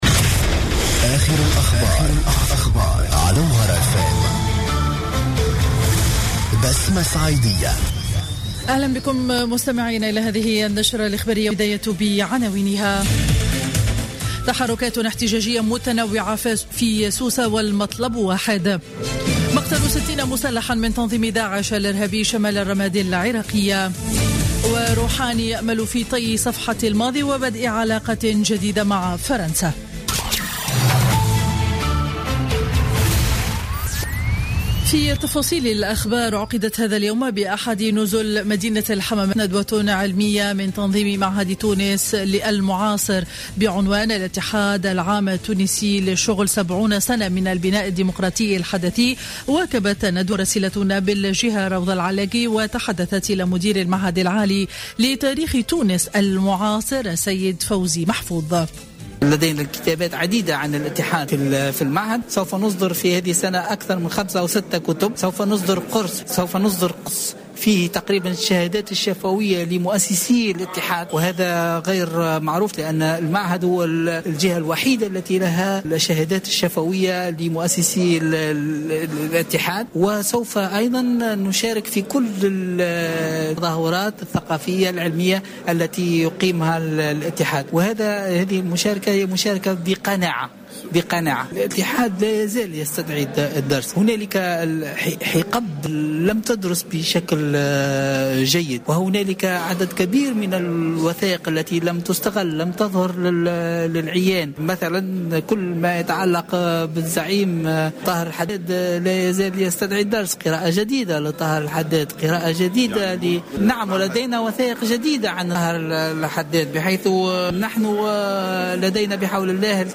نشرة أخبار منتصف النهار ليوم الخميس 28 جانفي 2016